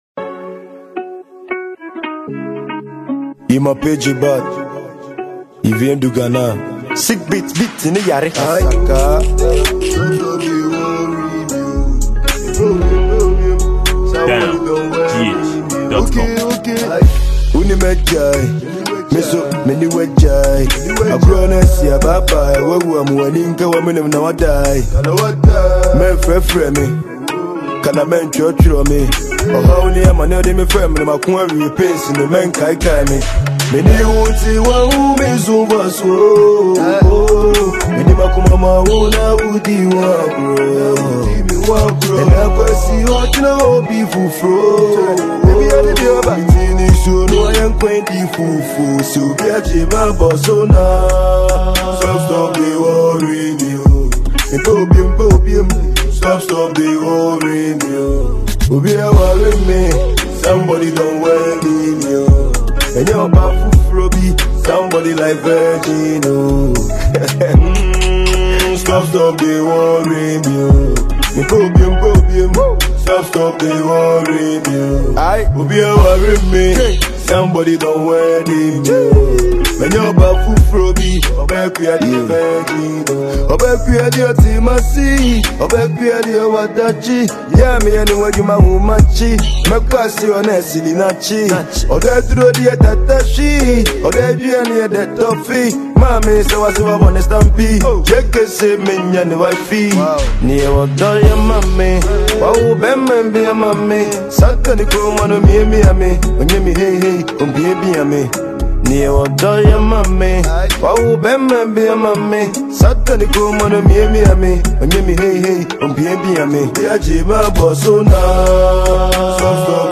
Genre: Drill